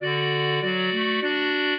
clarinet